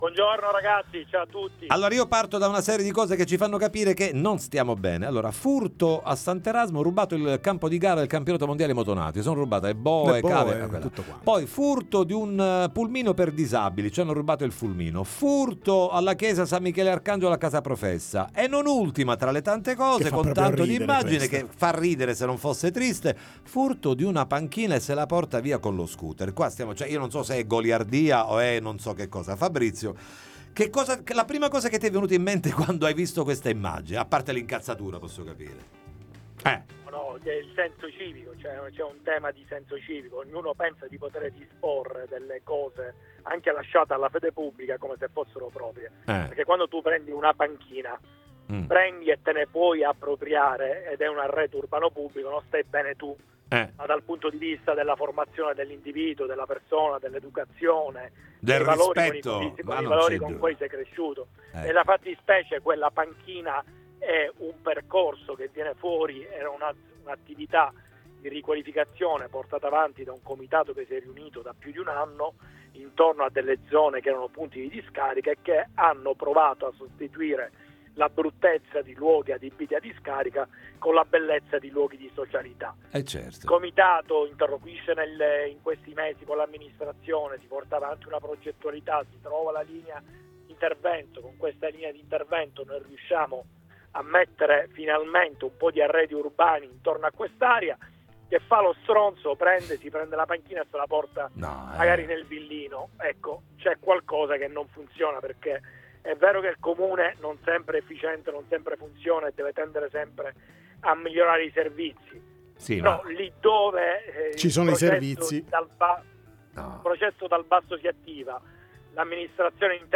Ruba una panchina e la carica sullo scooter, ne parliamo con Fabrizio Ferrandelli